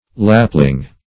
lapling - definition of lapling - synonyms, pronunciation, spelling from Free Dictionary
Search Result for " lapling" : The Collaborative International Dictionary of English v.0.48: Lapling \Lap"ling\, n. [Lap of a garment + ling.] One who has been fondled to excess; one fond of ease and sensual delights; -- a term of contempt.